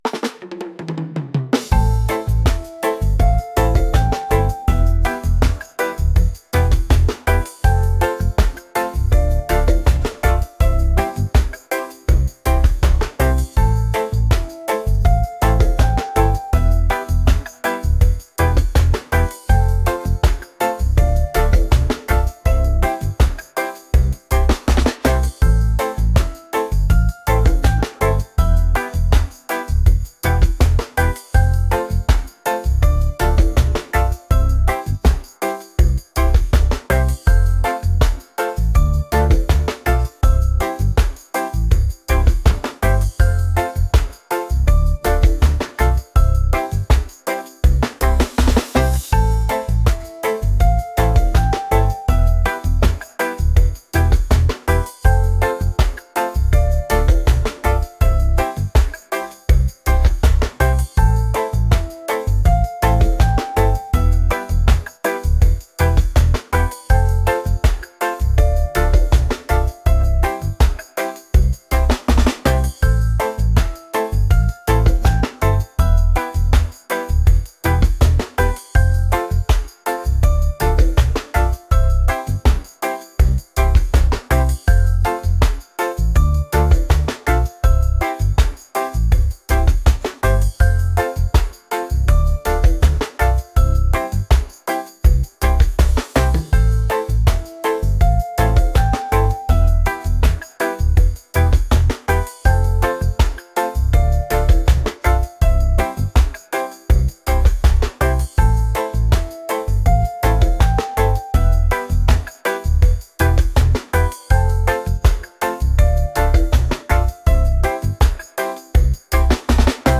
reggae | island | vibes | laid-back